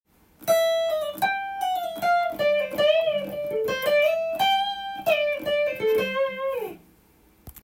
ギターソロが始まり２：１４～からのブルージーな音階が展開されます。
keyがCになっているのでCメジャースケールを使用しています。
時々クロマチックスケールを弾いているようです。